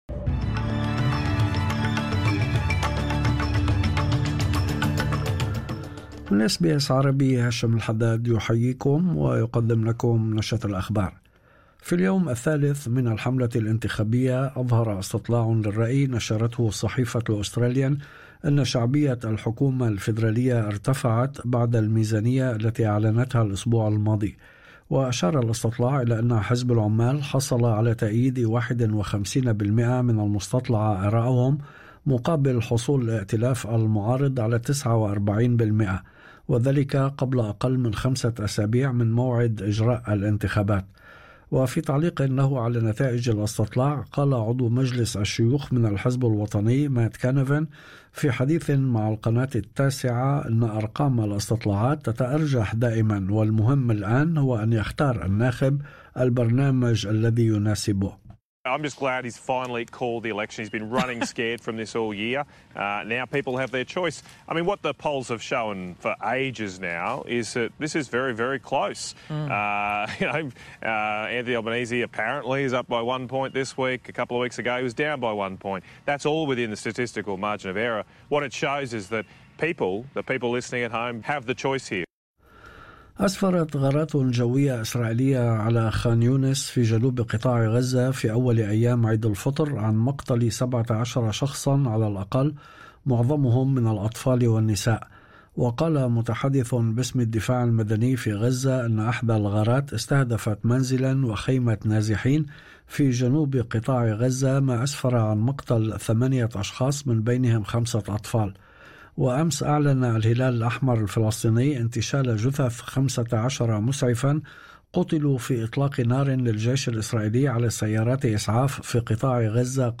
نشرة الأخبار